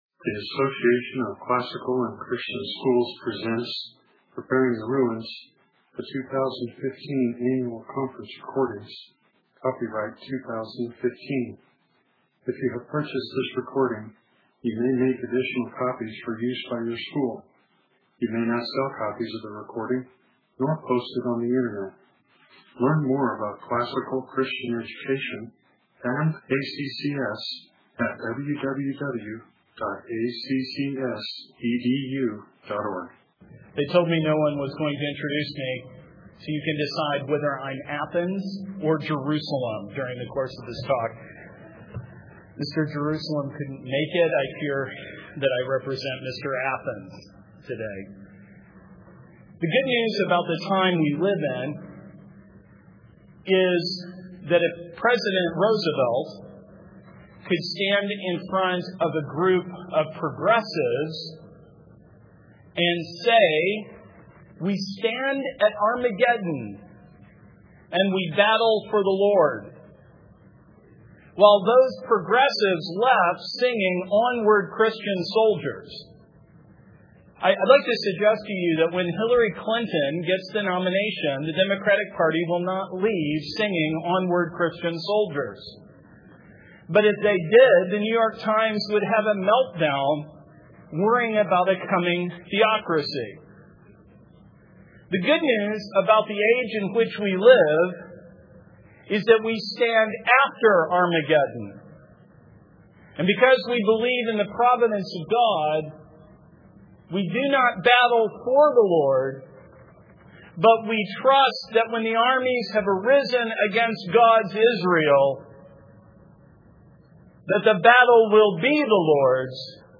2015 Workshop Talk | 0:57:42 | All Grade Levels, History, Philosophy